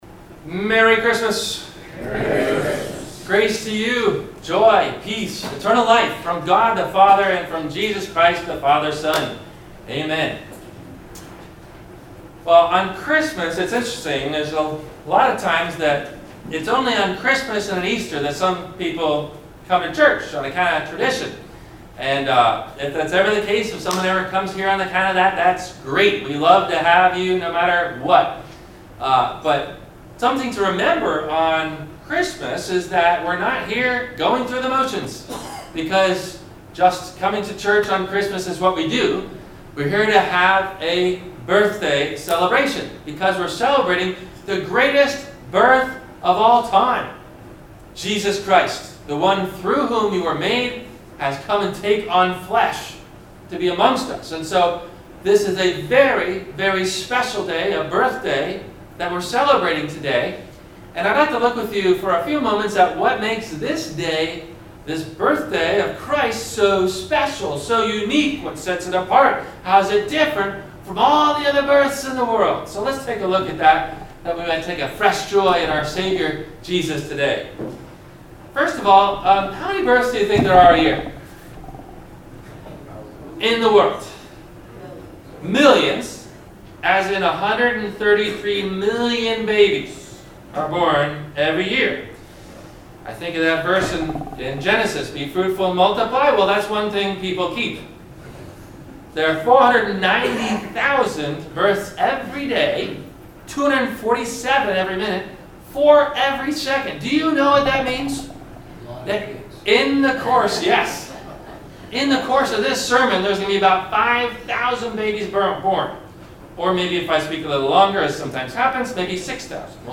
- Christmas Day - Sermon - December 25 2017 - Christ Lutheran Cape Canaveral